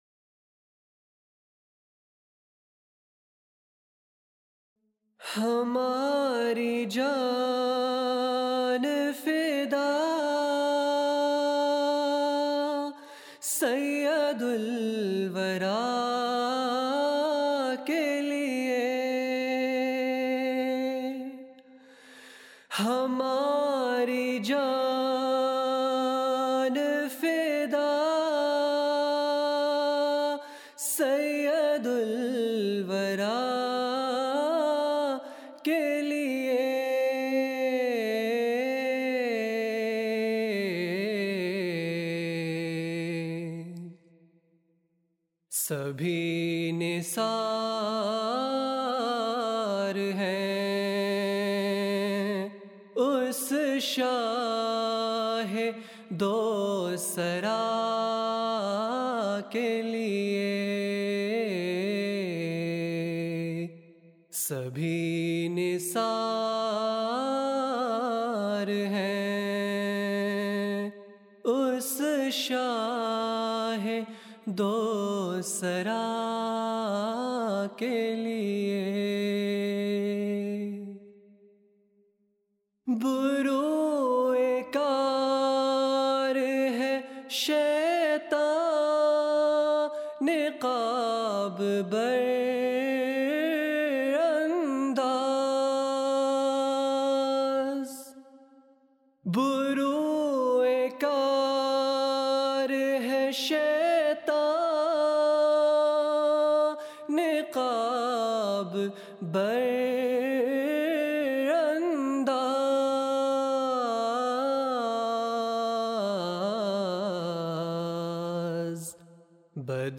نظمیں (Urdu Poems)
MTA 2007 (Jalsa Salana UK)